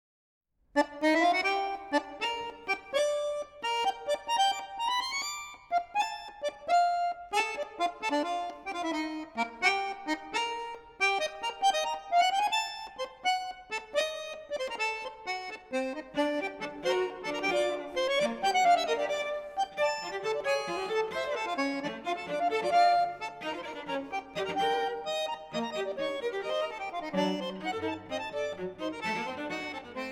accordion
violin
double bass
piano
percussion